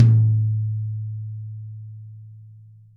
Index of /90_sSampleCDs/Best Service - Real Mega Drums VOL-1/Partition H/DRY KIT 2 GM